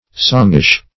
Songish \Song"ish\, a.